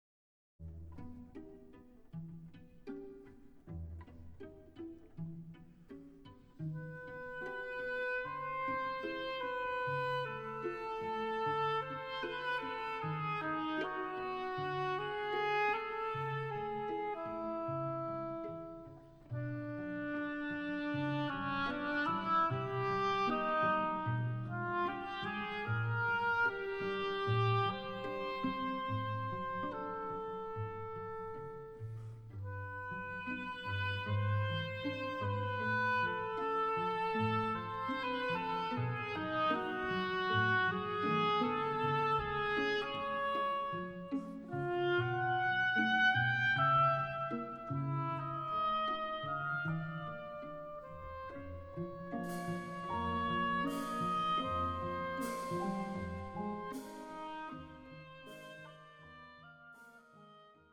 Dance Suite for Oboe, Strings, Piano and Percussion